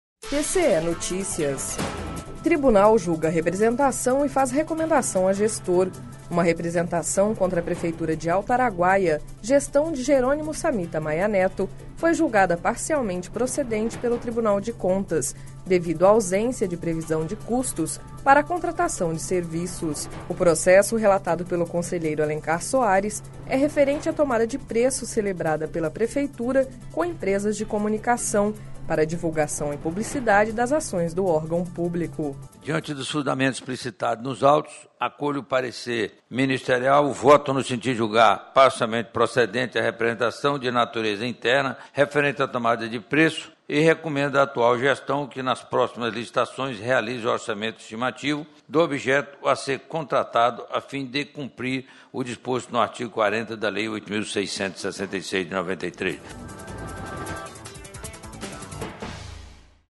Sonora: Alencar Soares - conselheiro do TCE-MT